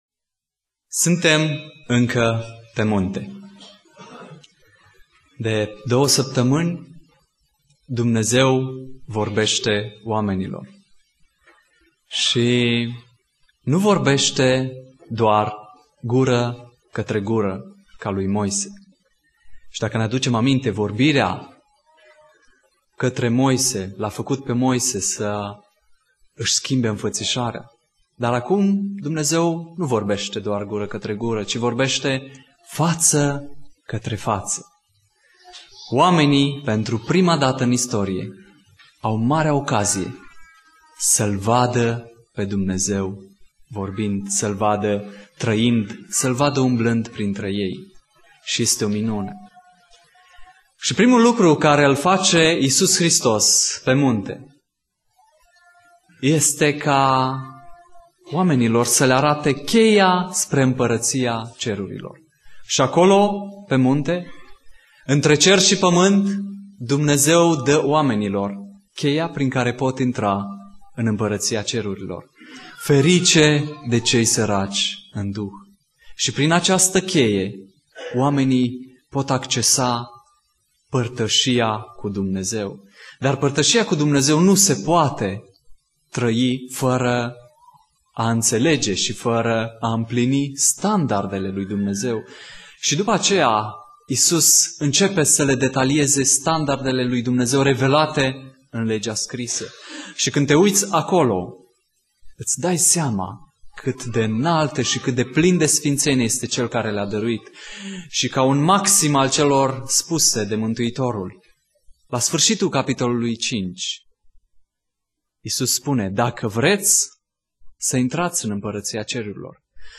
Predica Exegeza - Matei 6